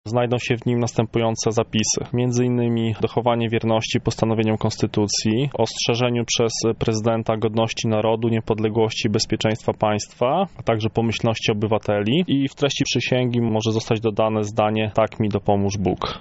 mówi politolog